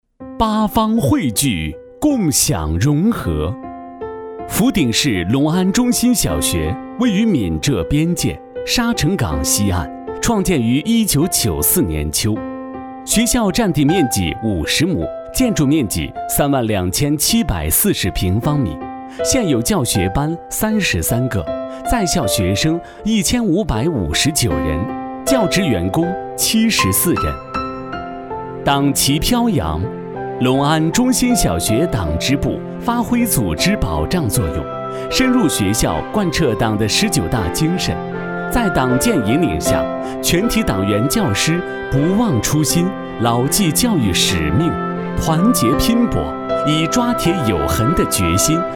小学男13号（年轻）
轻松自然 学校专题
年轻活泼男配音，磁性。擅自专题党建、宣传片、mg动画、课件解说等题材。